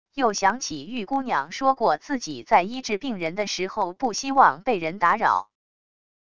又想起玉姑娘说过自己在医治病人的时候不希望被人打扰wav音频生成系统WAV Audio Player